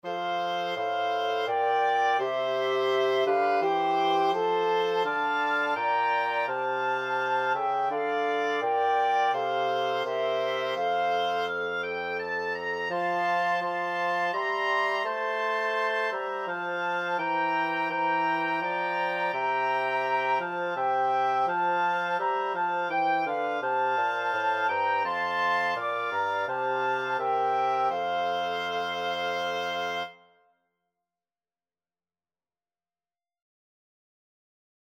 FluteOboeClarinetBassoon
3/4 (View more 3/4 Music)
Wind Quartet  (View more Easy Wind Quartet Music)